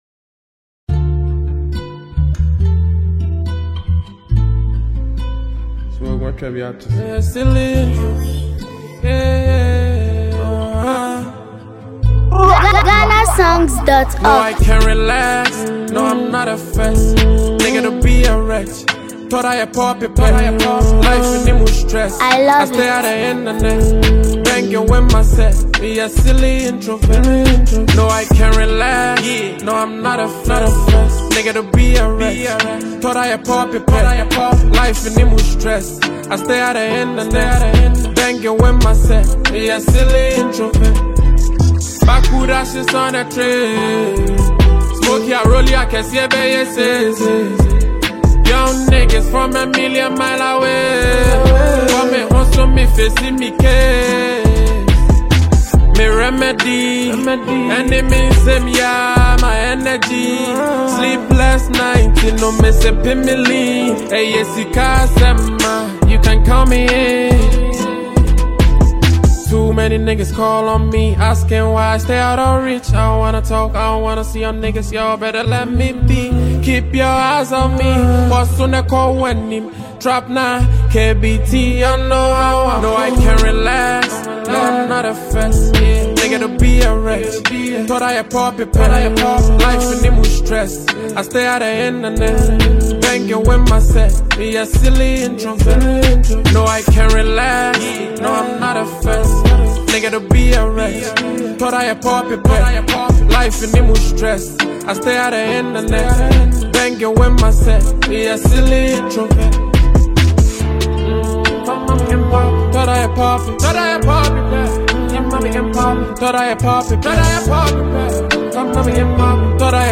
With a smooth Afro-fusion sound and heartfelt lyrics
blends soft melodies with thoughtful storytelling